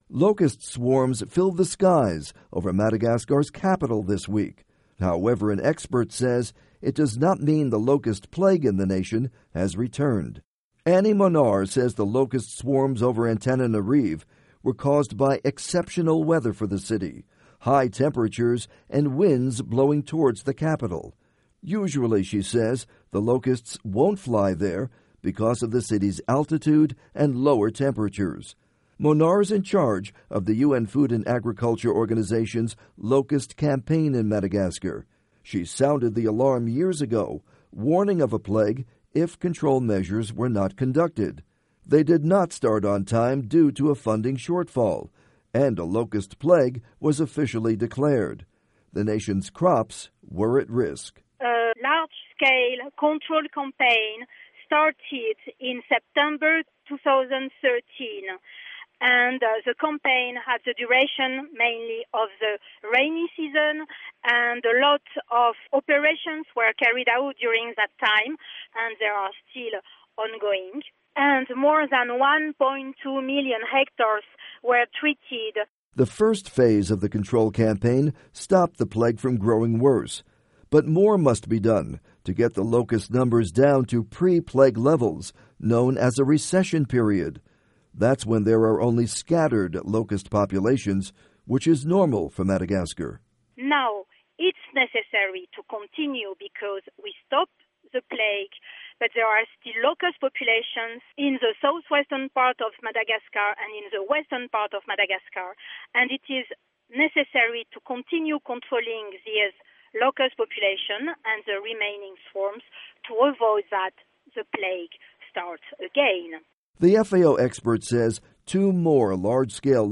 report on Madagascar locusts